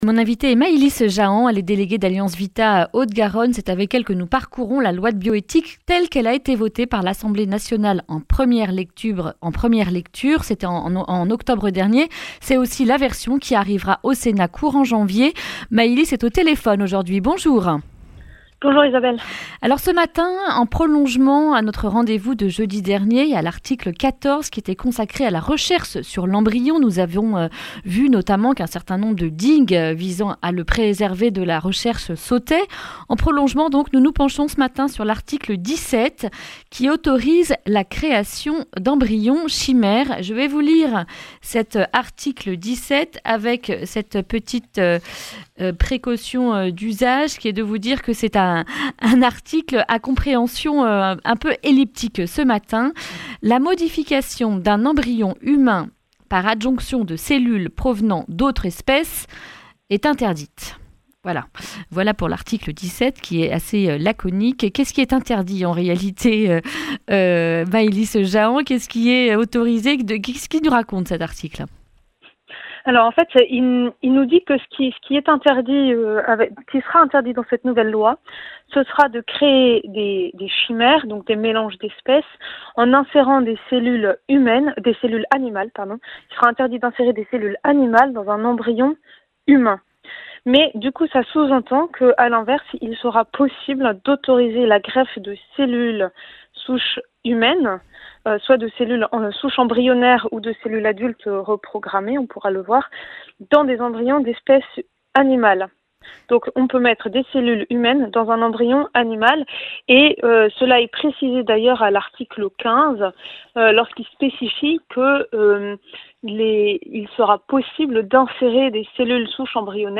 jeudi 12 décembre 2019 Le grand entretien Durée 10 min
Une émission présentée par